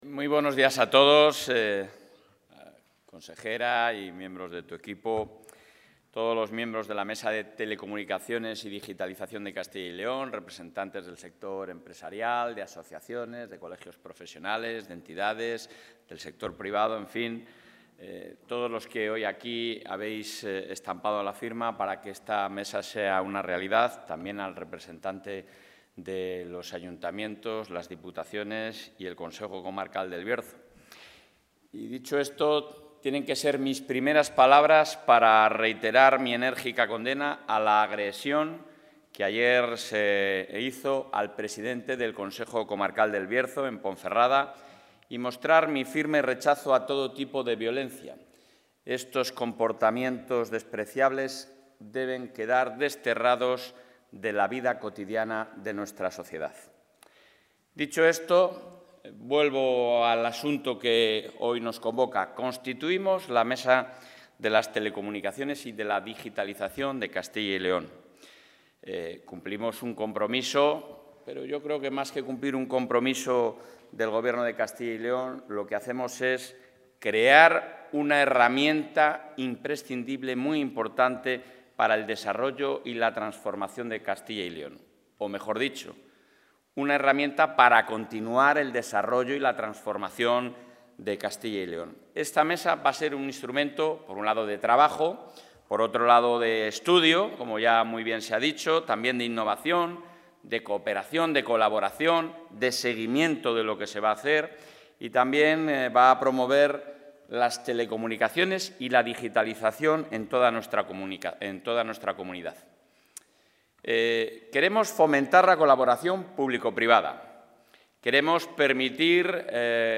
El presidente de la Junta de Castilla y León, Alfonso Fernández Mañueco, ha presidido hoy el acto de presentación de la...
Intervención del presidente de la Junta.